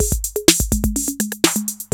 TR-808 LOOP1 5.wav